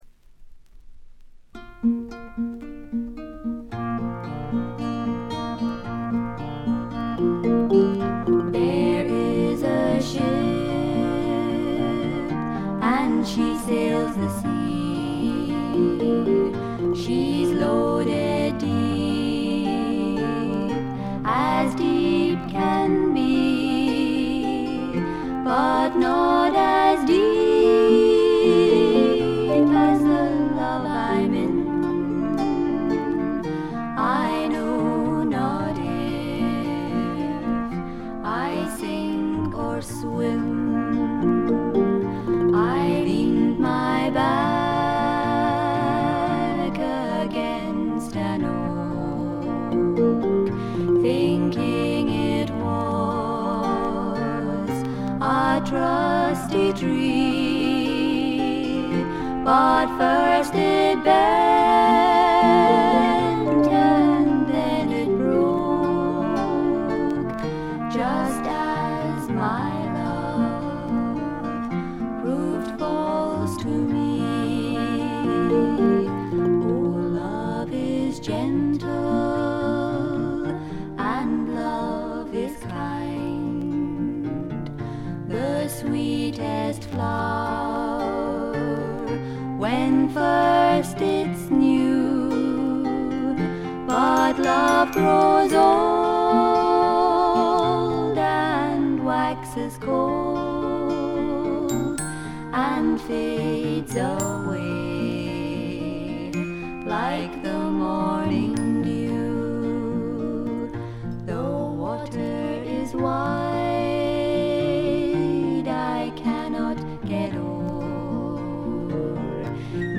軽微なチリプチやバックグラウンドノイズ、散発的なプツ音少々。
内容はまさしく天使の歌声を純粋に楽しめる全14曲です。
試聴曲は現品からの取り込み音源です。